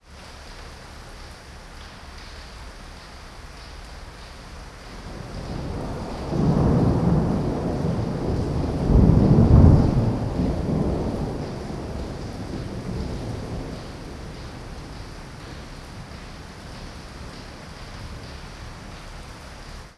rain_mono.wav